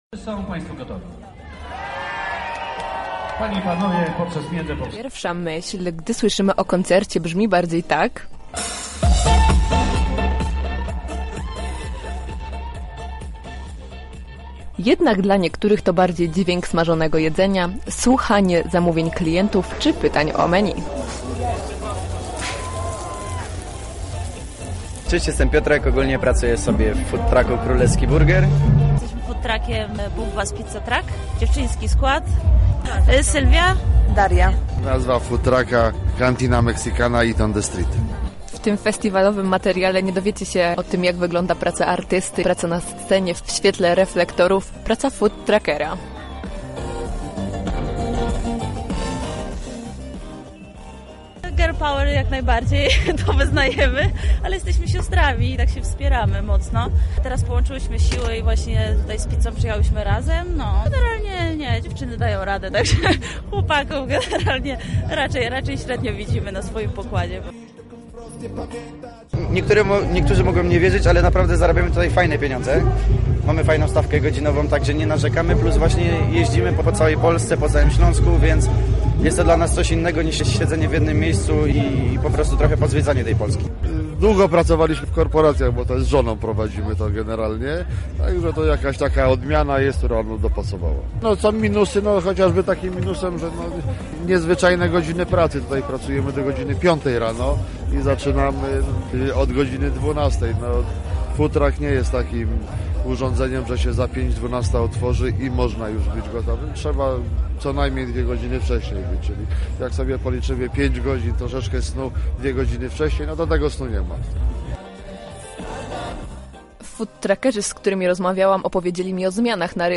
Opublikowano w Audycje, Menu kulturalne